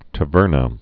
(tə-vûrnə, tä-vĕrnä)